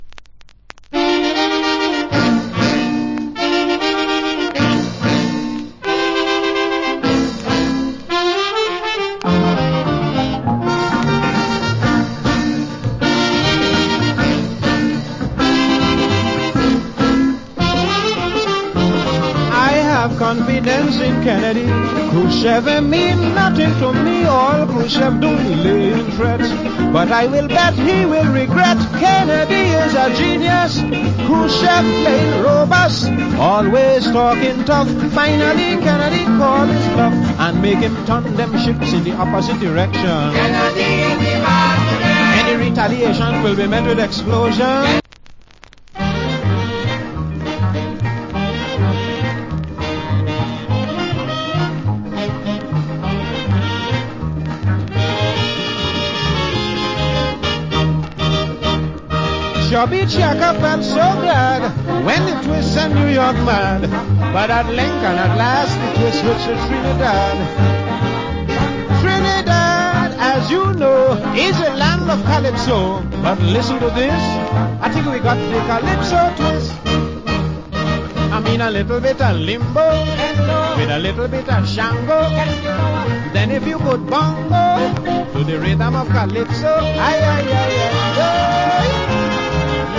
Wicked Calypso Vocal.